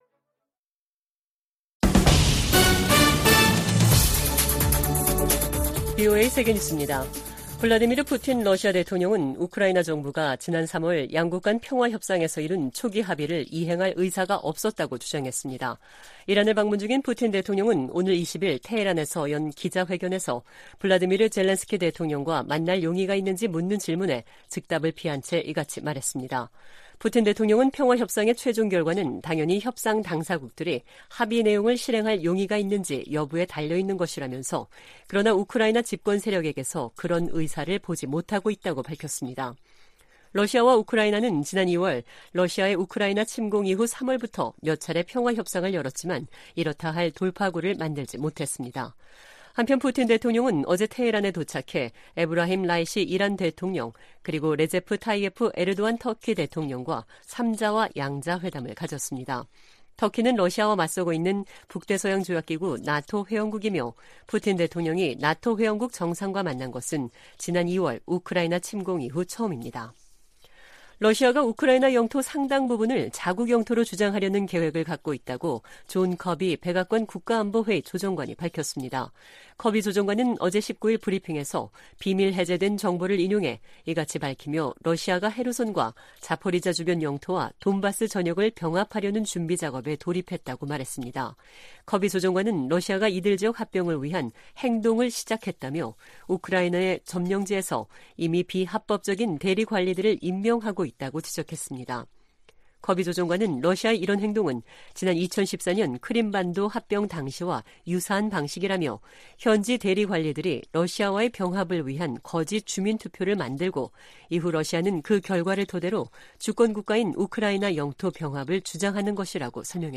VOA 한국어 간판 뉴스 프로그램 '뉴스 투데이', 2022년 7월 20일 3부 방송입니다. 미 국무부 ‘2022 인신매매 실태 보고서’에서 북한이 20년 연속 최악의 인신매매 국가로 지목됐습니다.